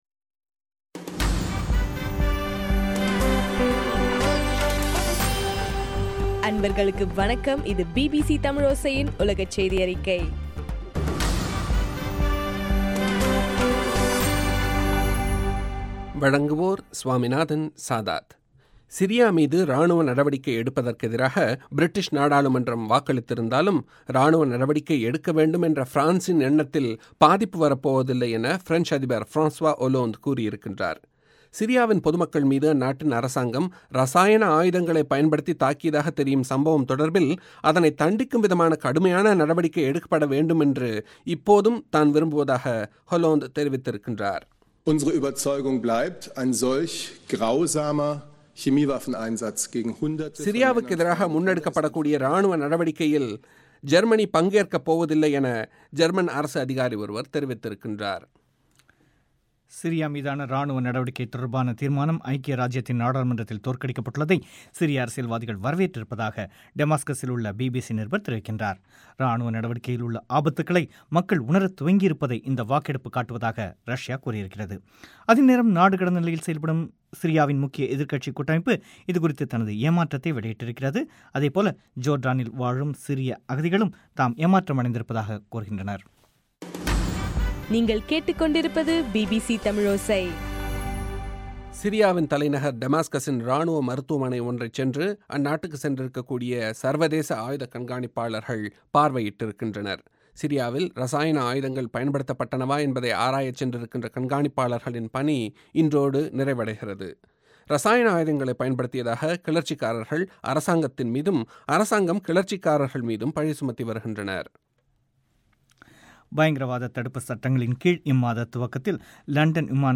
இலங்கையின் சக்தி எப்எம் வானொலியில் ஒலிபரப்பான உலகச் செய்தியறிக்கை